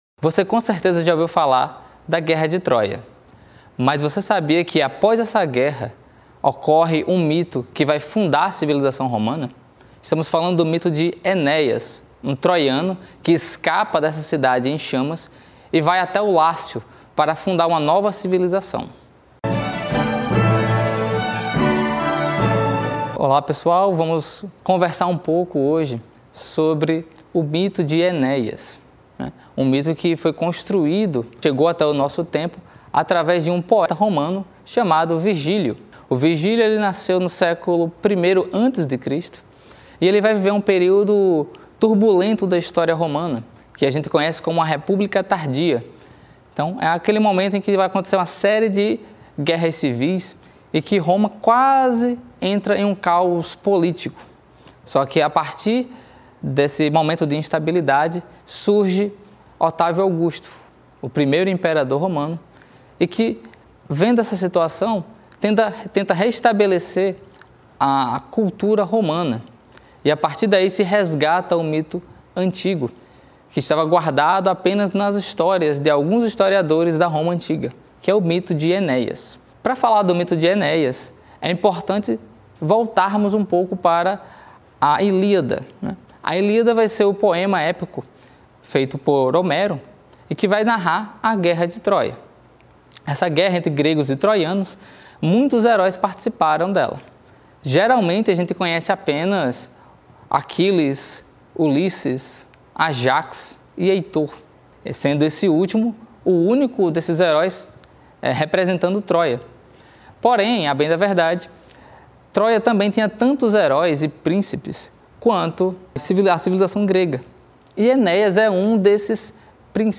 Nesta aula, conheça o mito de Enéias e compreenda seus significados filosóficos: a superação do caos, o sentido do dever, a continuidade entre passado, presente e futuro, e a construção interior que sustenta toda civilização.